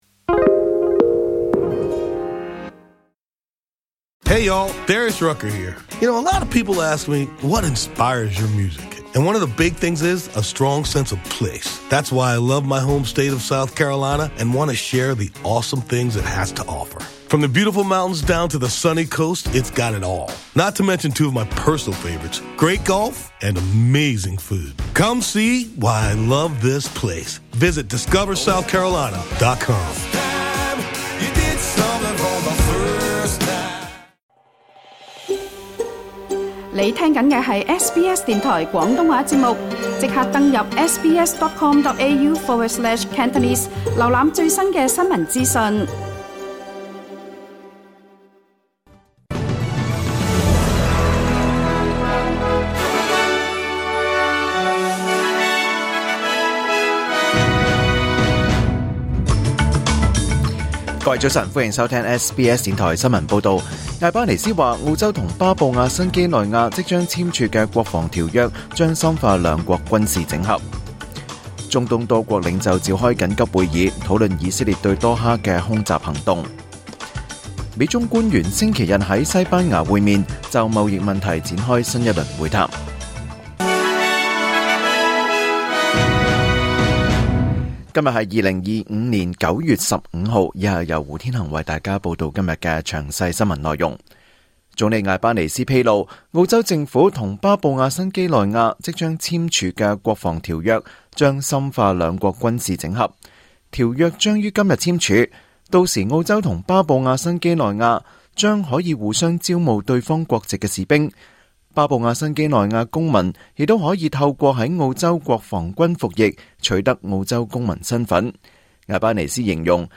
2025年9月15日 SBS 廣東話節目九點半新聞報道。